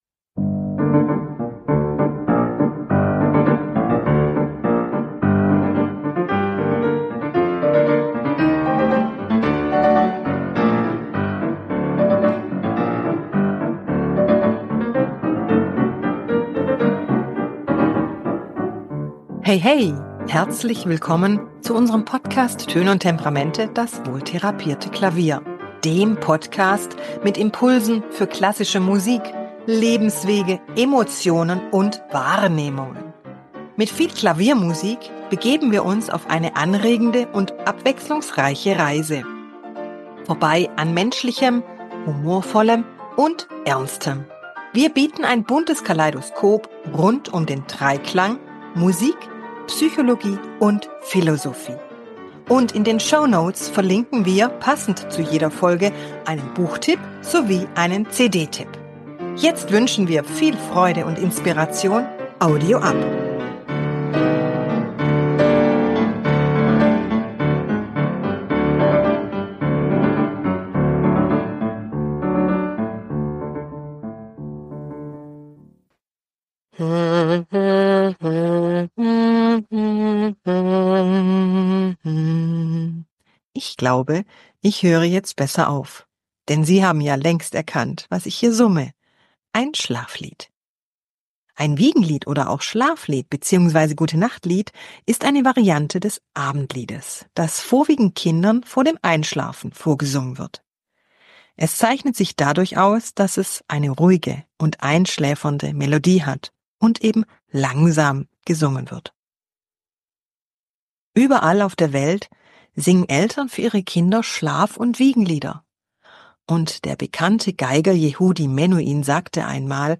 Wiegenlieder
Tenor
am Klavier